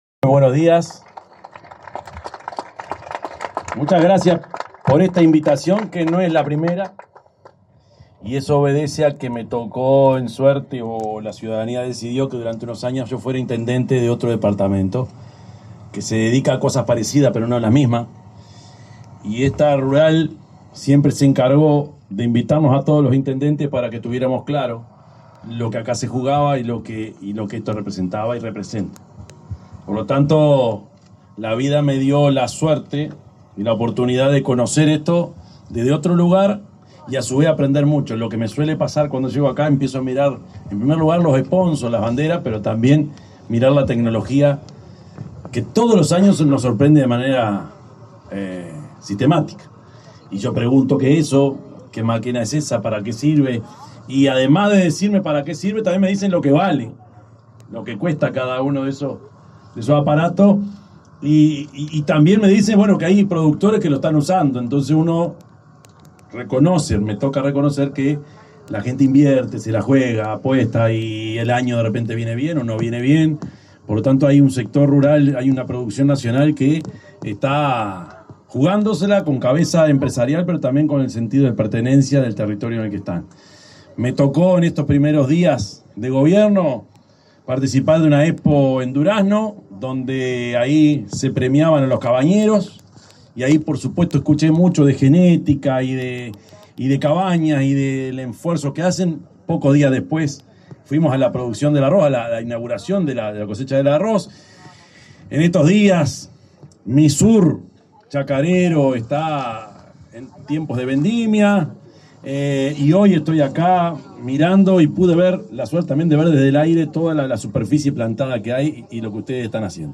Palabras del presidente de la República, Yamandú Orsi
Palabras del presidente de la República, Yamandú Orsi 19/03/2025 Compartir Facebook X Copiar enlace WhatsApp LinkedIn El presidente de la República, profesor Yamandú Orsi, participó, este 19 de marzo, en la inauguración de la 28.° Expoactiva, en el departamento de Soriano.